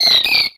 Audio / SE / Cries / AZURILL.ogg